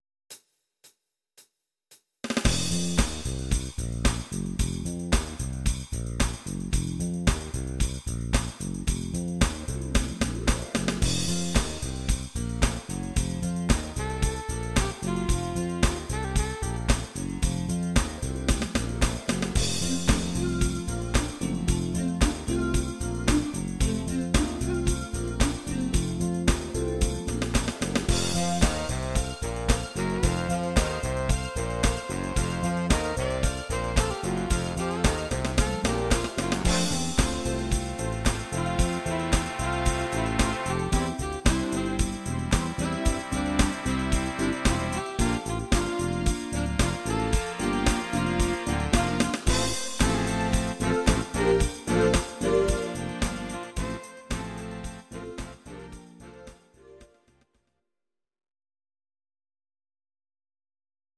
Audio Recordings based on Midi-files
Pop, 1970s